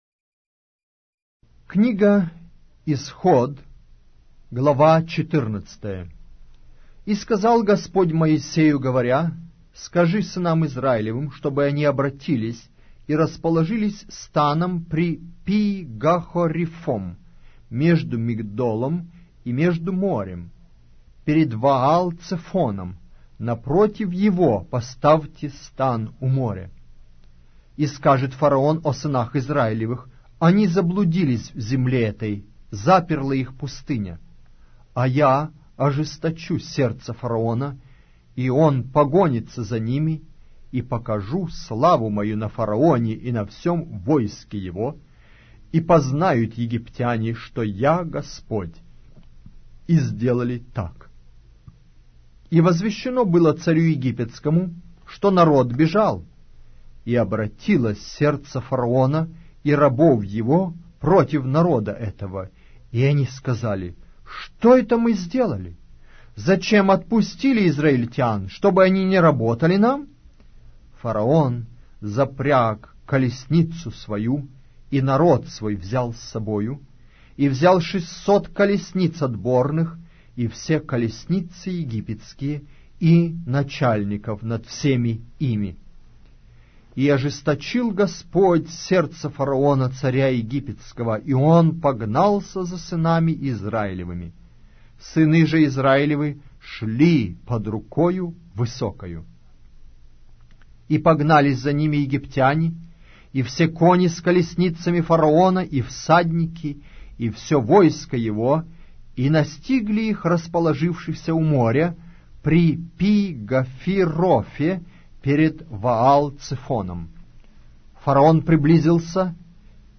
Аудиокнига: Книга 2-я Моисея. Исход